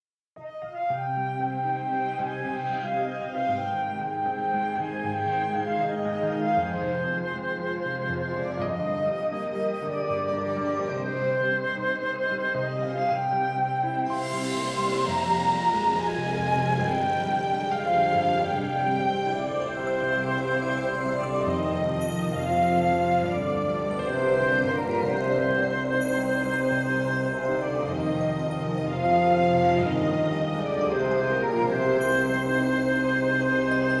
Feedback zu meiner Cinematic music Demo
Vor etwa einer Woche habe ich hier meine erste Demo hochgeladen – ein erster Versuch in Richtung cinematisches Instrumental.
Jetzt folgt mein zweiter Versuch, diesmal bewusst kürzer gehalten (ca. 30 Sekunden), dafür etwas fokussierter.